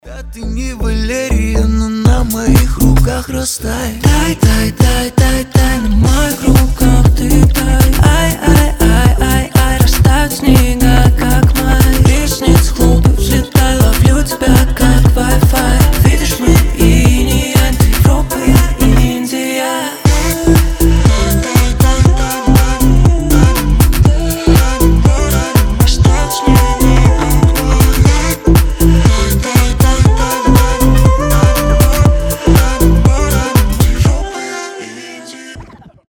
поп , дуэт , легкие